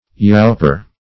yauper - definition of yauper - synonyms, pronunciation, spelling from Free Dictionary Search Result for " yauper" : The Collaborative International Dictionary of English v.0.48: Yauper \Yaup"er\, n. One who, or that which, yaups.